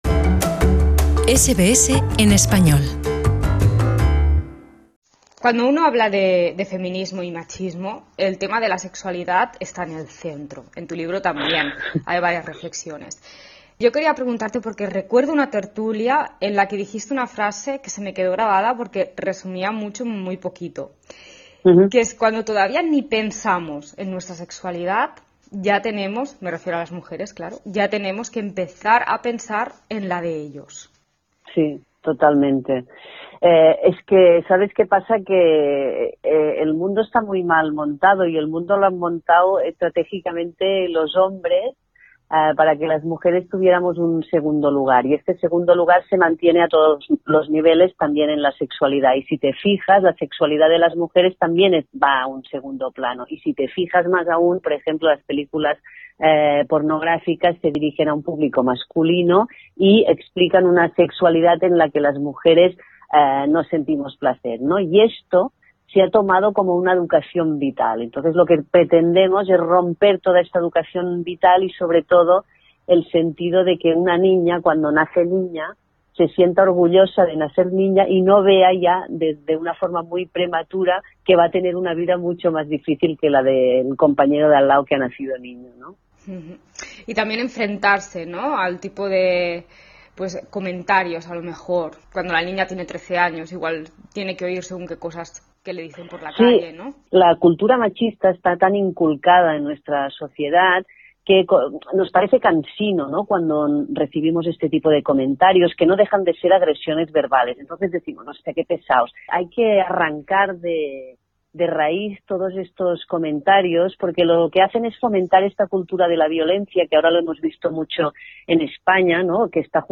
Escucha esta picante entrevista